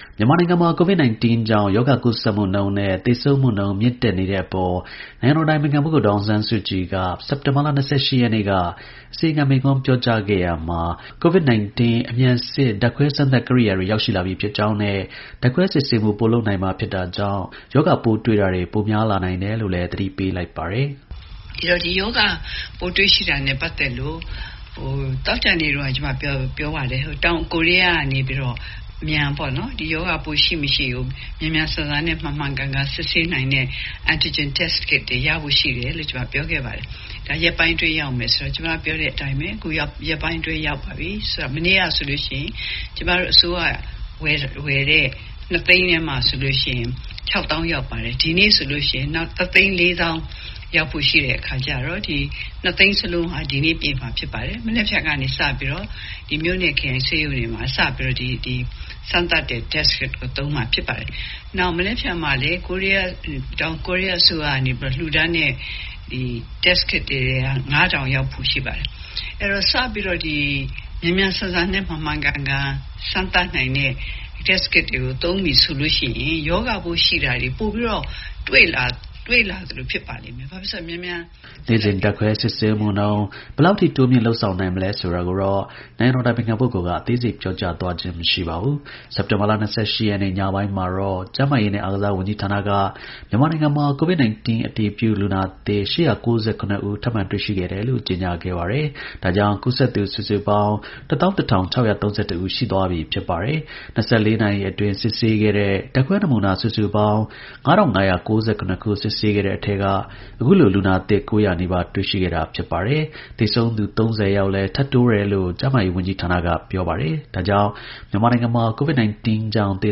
နိုင်ငံတော် အတိုင်ပင်ခံ ဒေါ် အောင်ဆန်းစုကြည်က အစီရင်ခံမိန့်ခွန်းပြောကြားခဲ့ရာမှာ တောင်ကိုရီးယားနိုင်ငံကမှာယူထားတဲ့ အမြန် စစ် ဓါတ်ခွဲ စမ်းသပ် ကိရိယာတွေရောက် ရှိ လာပြီ ဖြစ်ကြောင်းနဲ့ ဓါတ်ခွဲ စစ်ဆေးမှု ပိုလုပ်တာကြောင့် ရောဂါ ပိုးတွေ့တာ တွေ ပိုများလာနိုင်တယ်လို့လည်း သတိပေးလိုက် ပါတယ်။